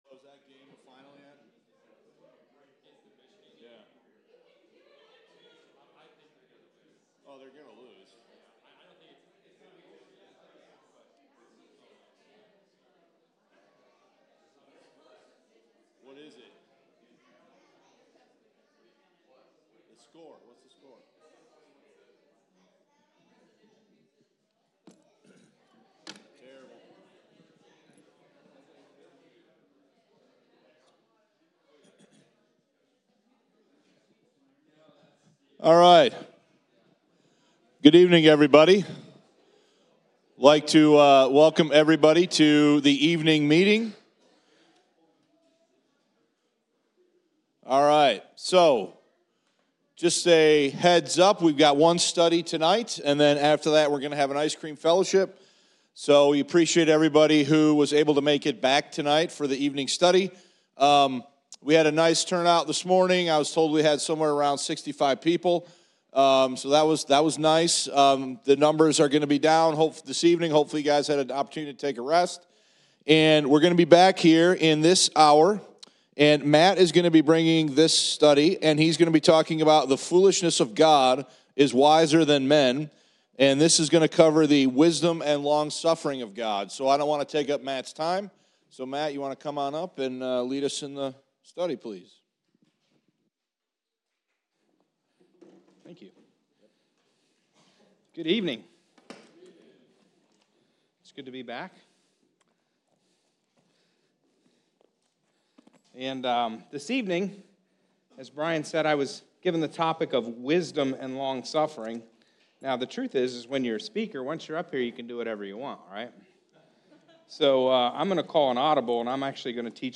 2024 West Michigan Grace Bible Conference } Understanding The Nature & Character of God The Father